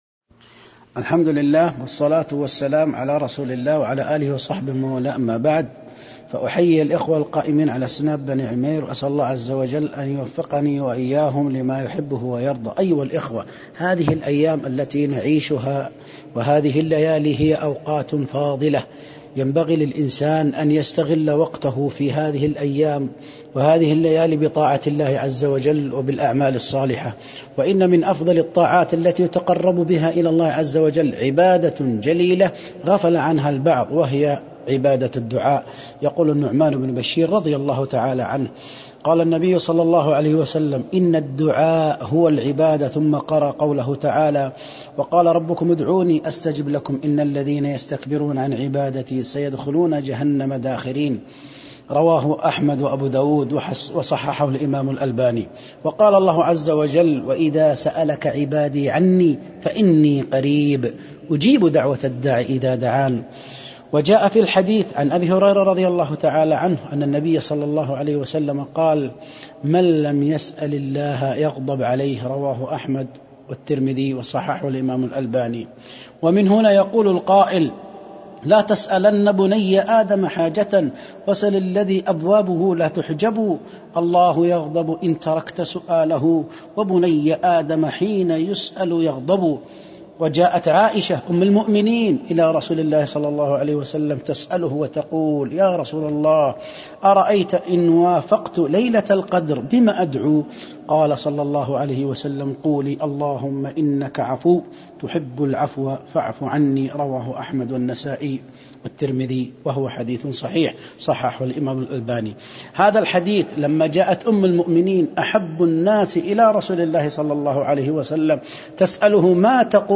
كلمة قيمة ألقيت في 24رمضان1441هجرية